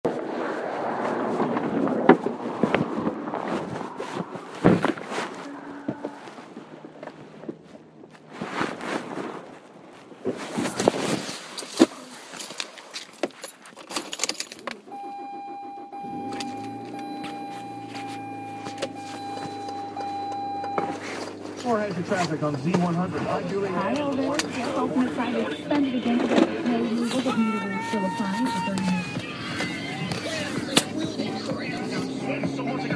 Field Recording #1
Location: My home on the morning of February 7th.
Sounds Heard: My footsteps in the snow, cars rushing past, keys gangling, car door opening, beeping from the car, ticking of the signal, radio, me shifting from park to drive,the brushing of my sleeves as I turn the wheel.